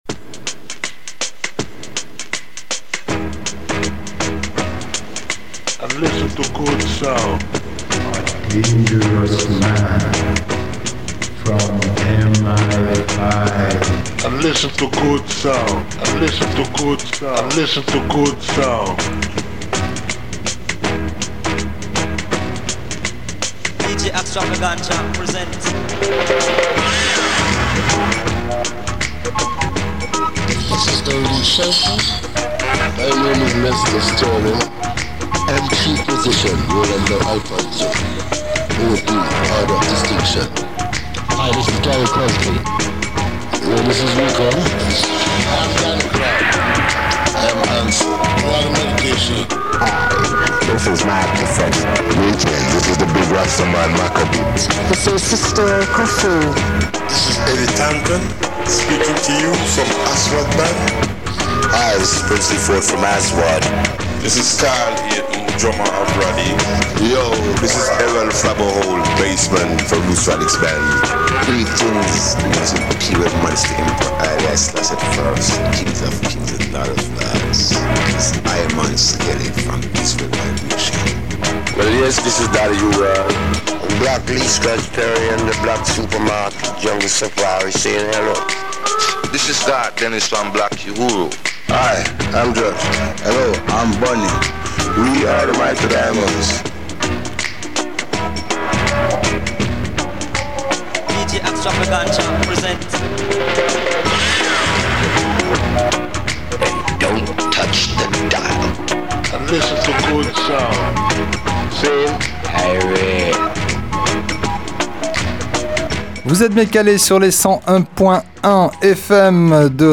Black Super Market – radio show !
dub, salsa, funk, mestizo, ska, afrobeat, reggaeton, kompa, rumba, reggae, soul, cumbia, ragga, soca, merengue, Brésil, champeta, Balkans, latino rock…